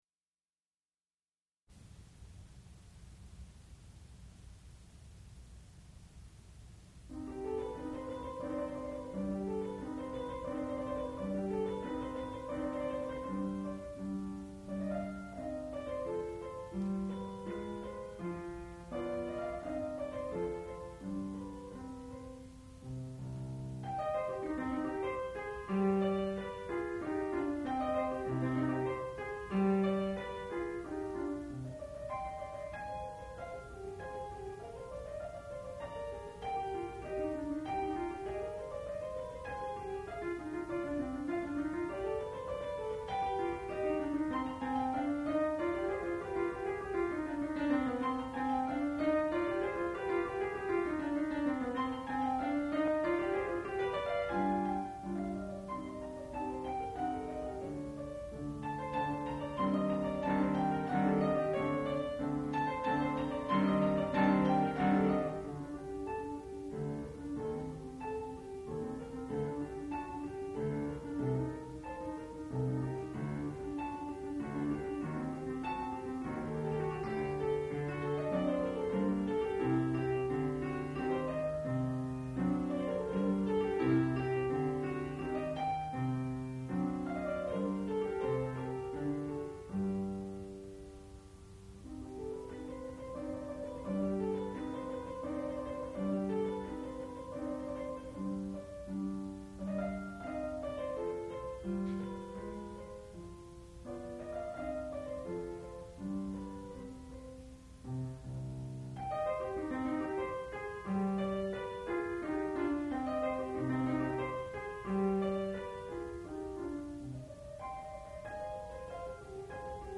sound recording-musical
classical music
piano
Graduate Recital